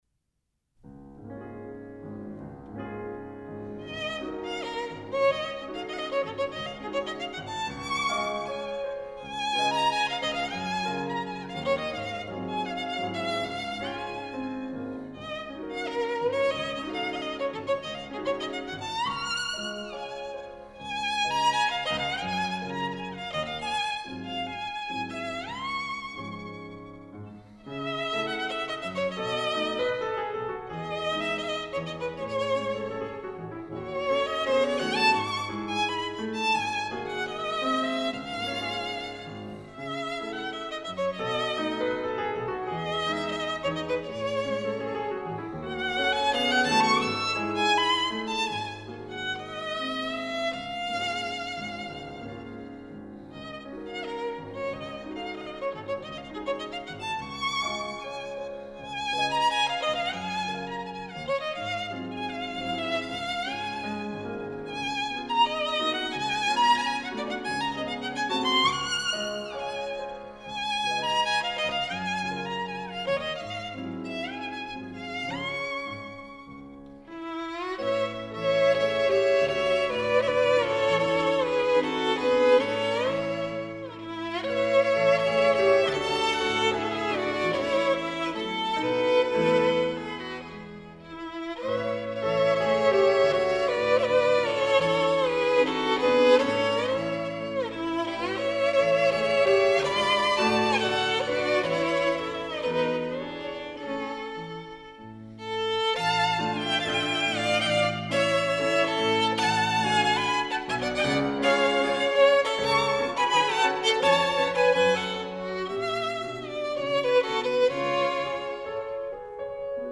violon
piano